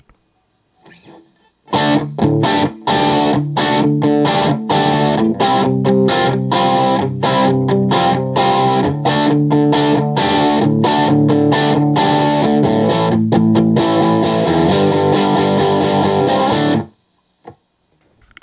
The 2015 Newark Street (NS) S-100 has a much chimier tone than the 1997 S-100.
All recordings in this section were recorded with an Olympus LS-10. Distorted amp sounds are an Axe-FX Ultra set to “Basic Brit 800” with no effects.
2015 S-100 Bridge Pickup
The first sound bytes are just some A-shape barre chord riffs starting on the fifth fret with some sus-4 embellishment thrown in for good measure. This was recorded using the bridge pickup with all guitar controls on 10.